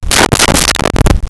Gorilla Chest Pound
Gorilla Chest Pound.wav